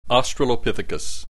click this icon to hear the preceding term pronounced